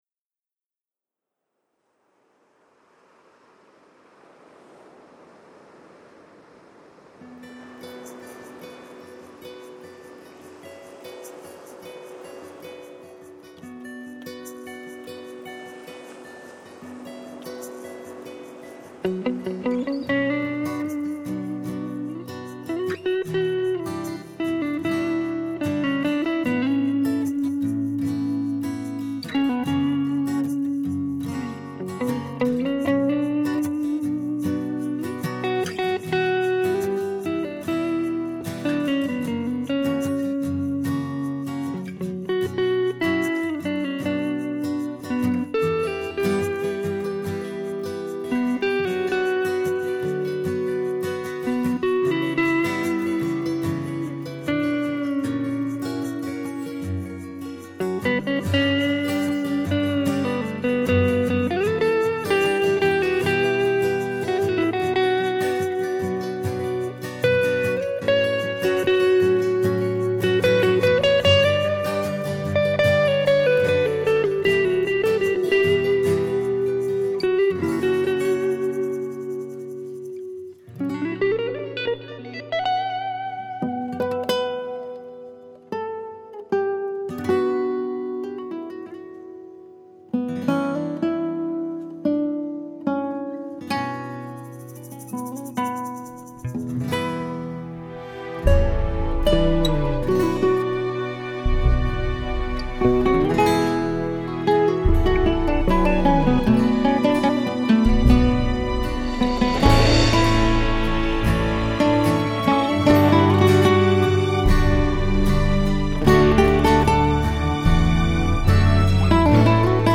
Genre: Instrumental Ballad